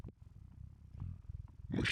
• demonic techno voice "machine".wav
Changing the pitch and transient for a studio recorded voice (recorded with Steinberg ST66), to sound demonic/robotic.